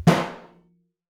timpsnaretenor_ff.wav